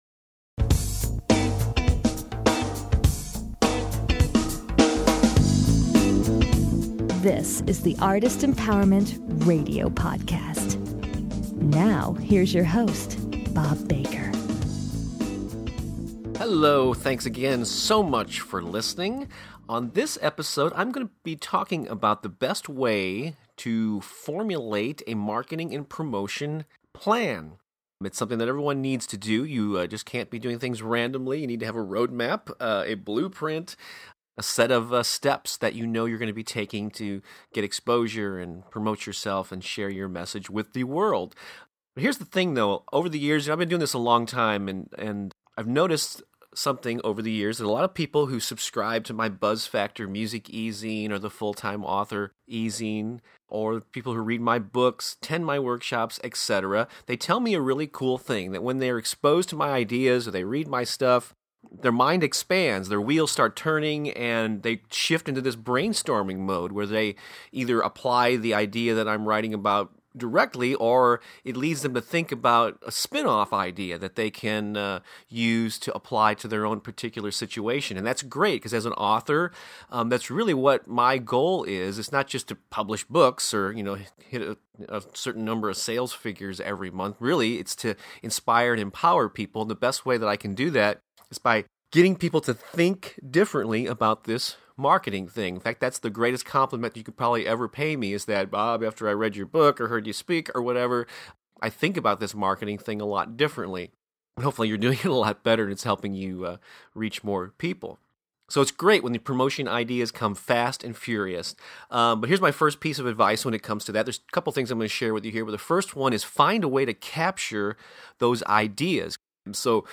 The show intro music is the beginning groove